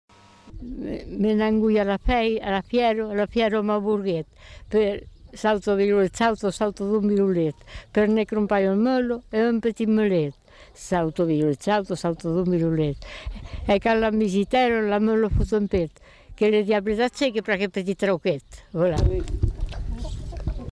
Genre : chant
Effectif : 1
Type de voix : voix de femme
Production du son : récité
Classification : coq-à-l'âne et facéties